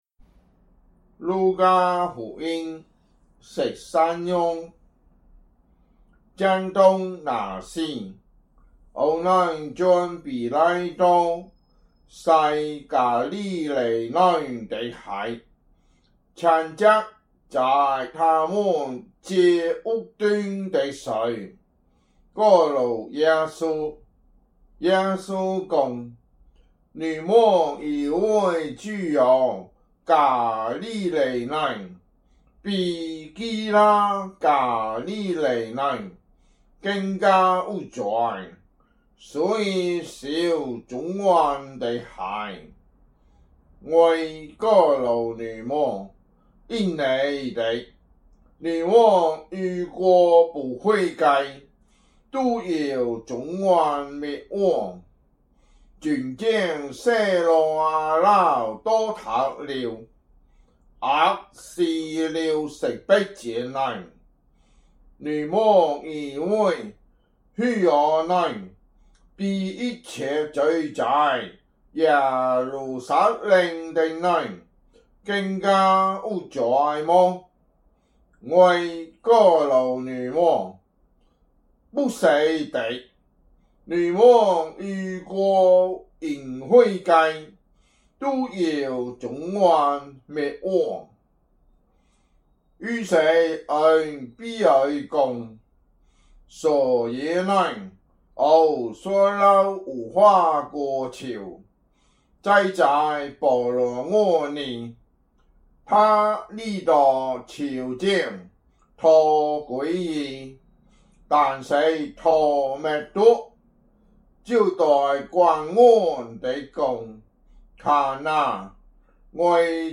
福州話有聲聖經 路加福音 13章